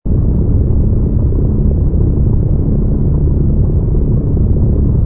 rumble_bw_fighter.wav